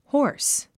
hoarse 発音 hɔ’ː r s ホース